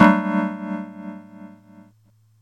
F#MIN9.wav